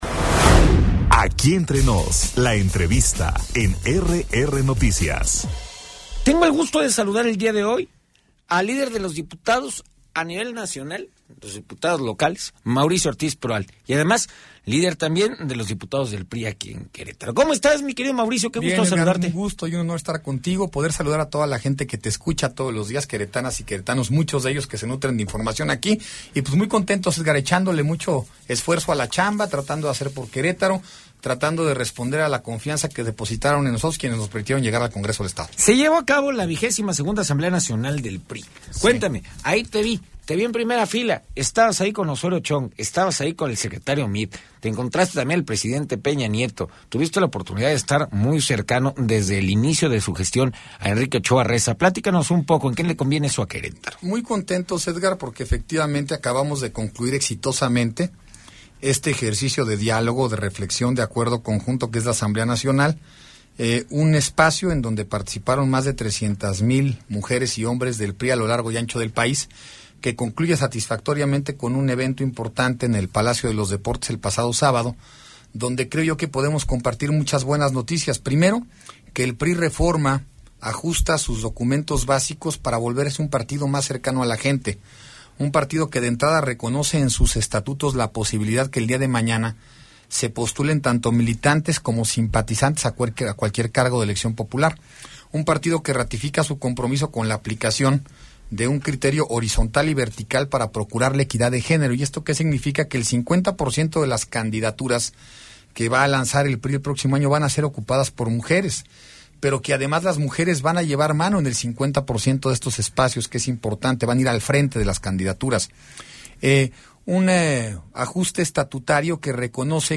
Entrevista con Mauricio Ortiz Proal, diputado local líder de la bancada priista